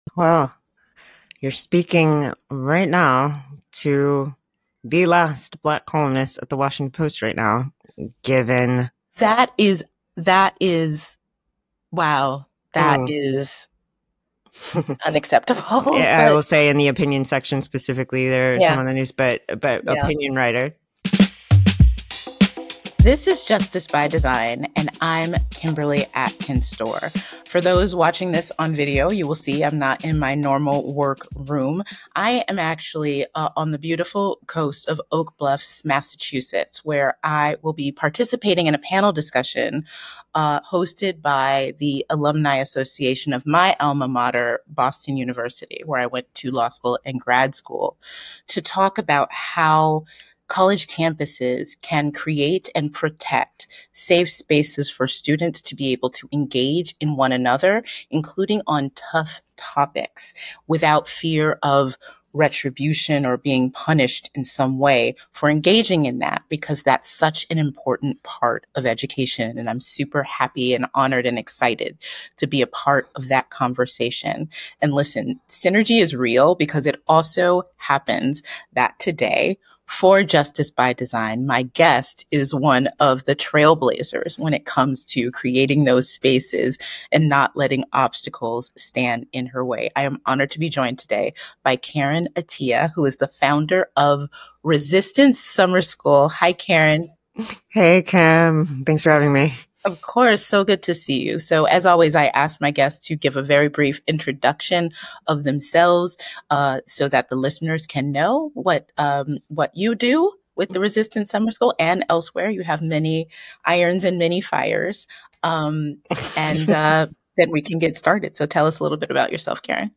Kimberly Atkins Stohr speaks with Karen Attiah, founder of Resistance Summer School, about the importance of creating safe spaces for discussions on tough topics in education.
The conversation highlights the role of librarians in fostering community and the need for journalists to document history amidst challenges in the media landscape.